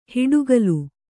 ♪ huḍugalu